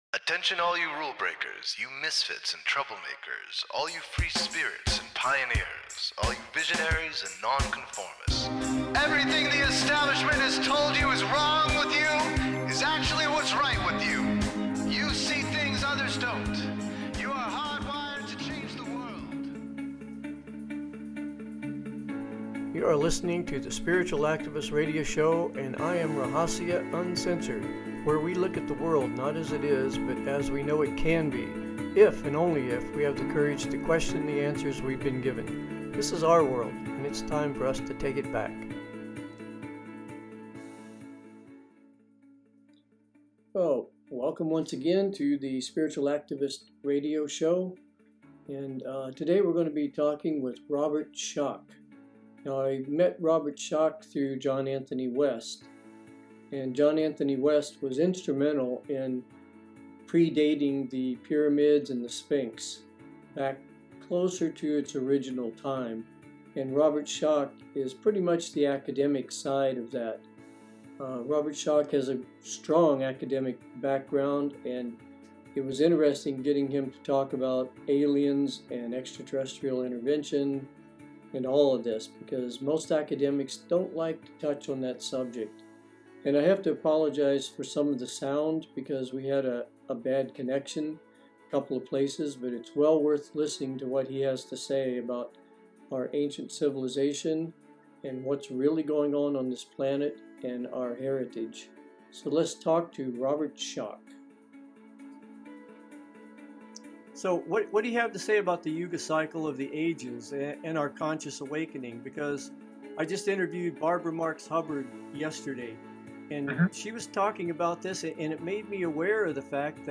Talk Show Episode
Guest, Robert Schoch